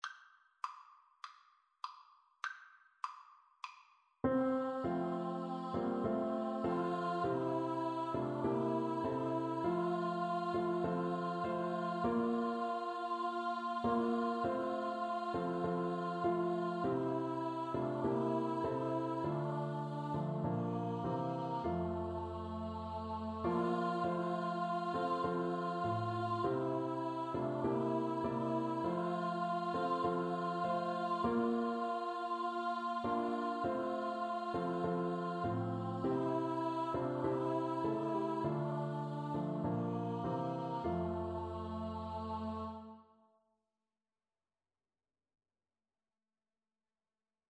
Free Sheet music for Choir (SATB)
Scottish